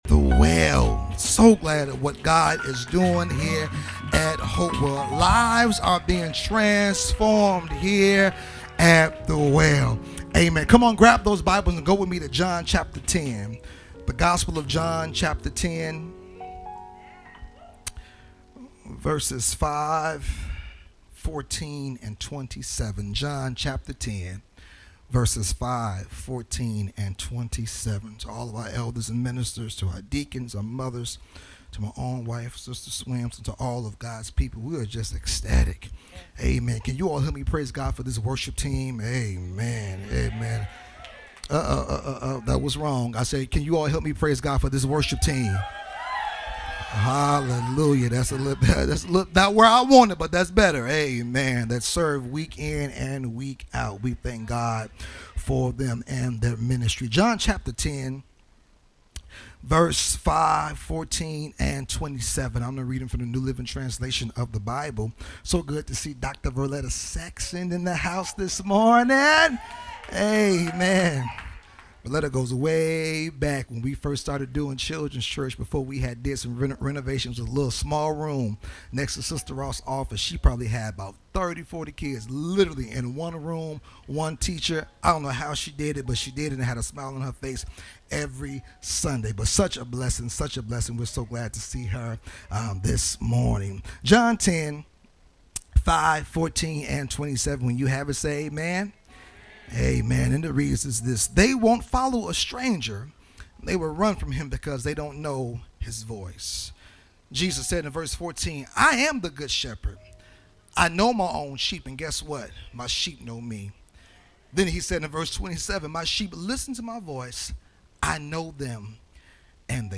Hopewell Missionary Baptist Church, Carbondale IL